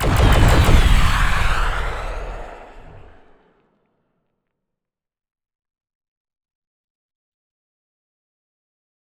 weapons